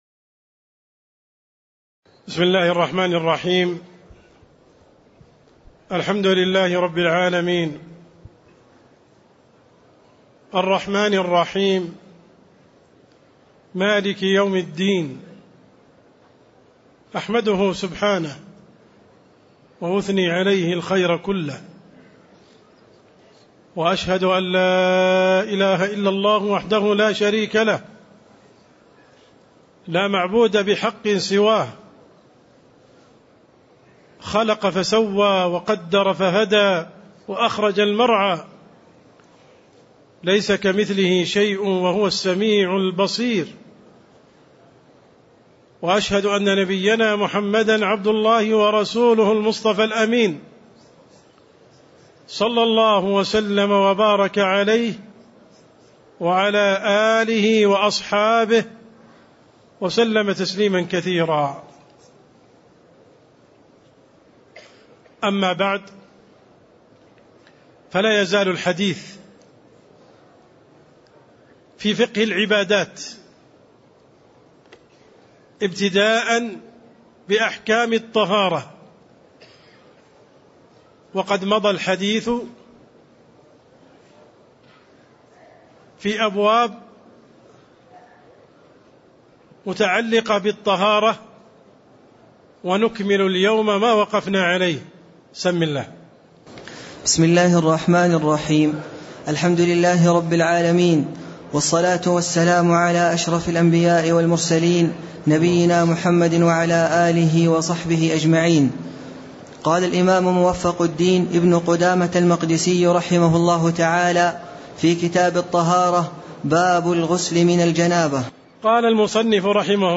تاريخ النشر ٢٨ رجب ١٤٣٥ هـ المكان: المسجد النبوي الشيخ